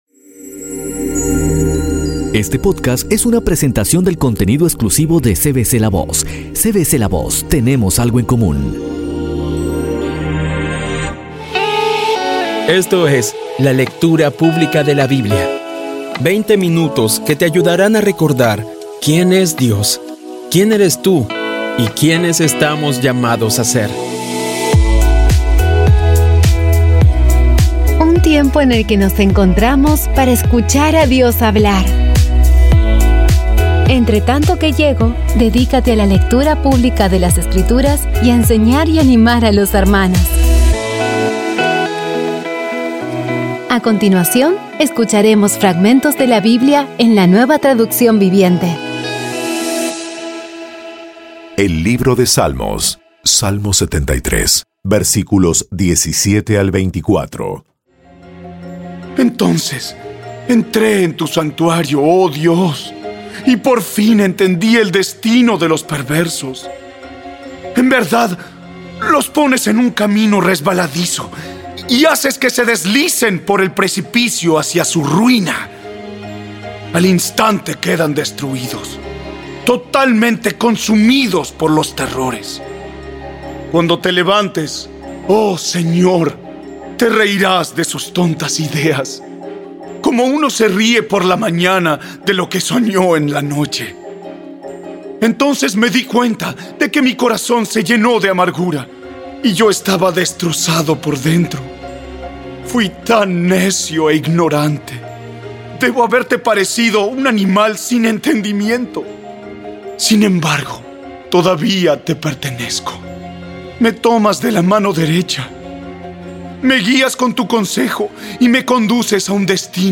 Audio Biblia Dramatizada Episodio 170
Poco a poco y con las maravillosas voces actuadas de los protagonistas vas degustando las palabras de esa guía que Dios nos dio.